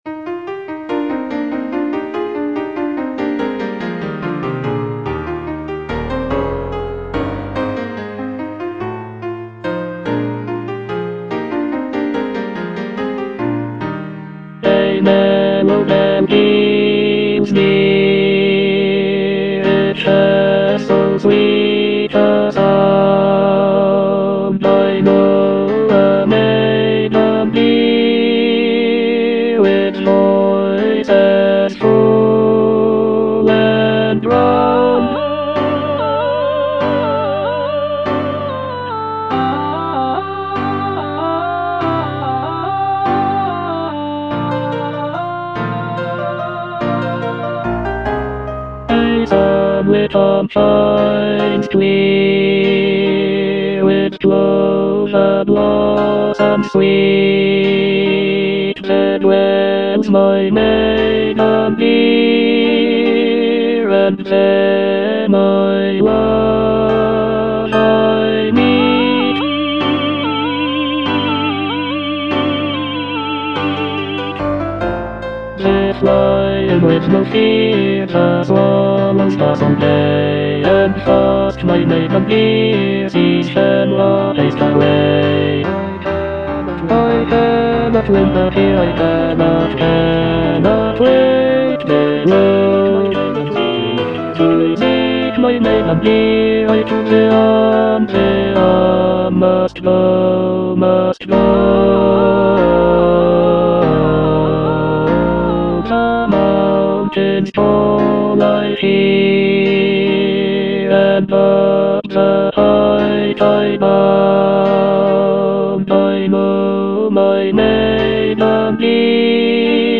(bass I) (Emphasised voice and other voices) Ads stop